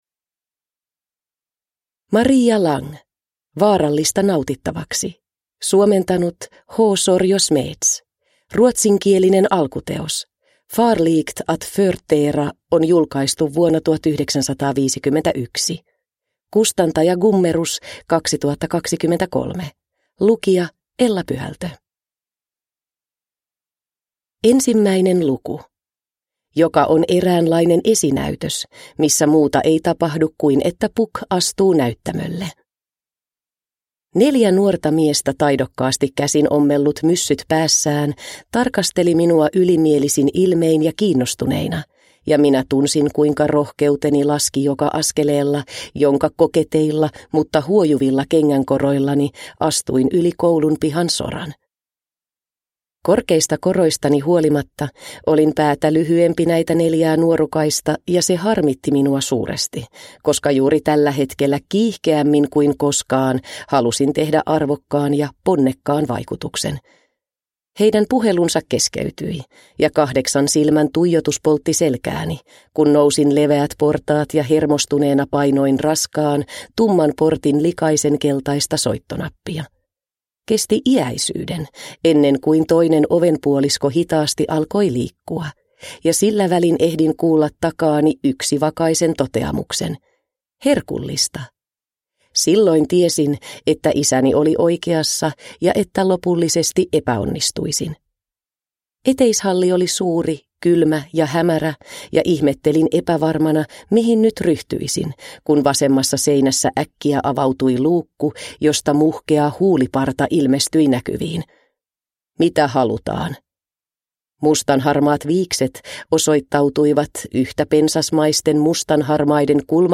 Vaarallista nautittavaksi – Ljudbok – Laddas ner